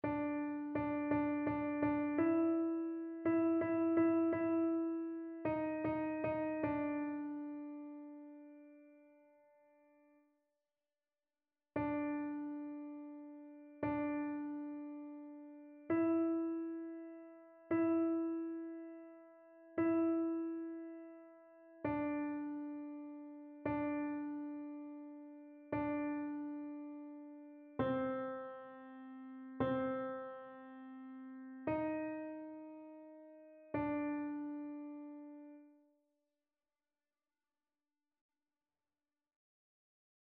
Alto
annee-c-temps-ordinaire-23e-dimanche-psaume-89-alto.mp3